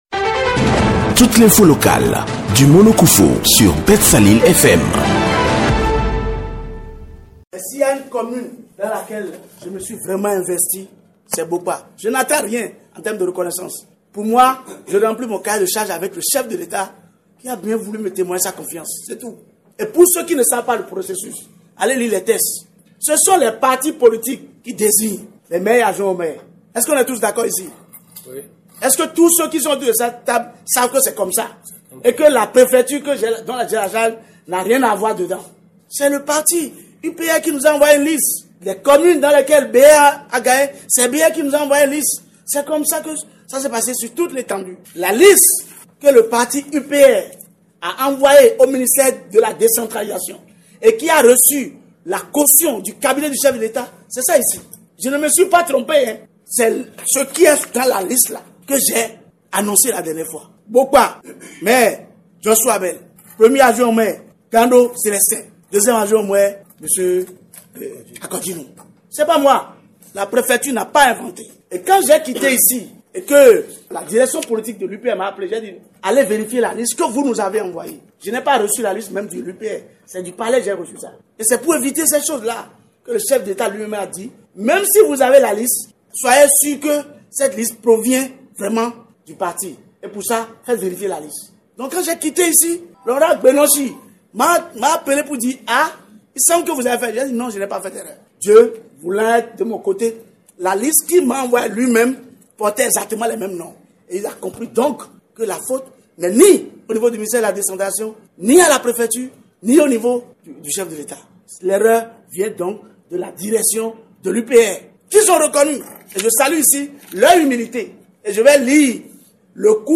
Selon les explications du préfet du Mono, cette erreur matérielle ne provenait pas de la préfecture mais plutôt du parti UPR contrairement aux informations distillées sur les réseaux sociaux et dans l’opinion publique. Voici un extrait des propos de Dêdêgnon Bienvenu Milohin, préfet du Mono.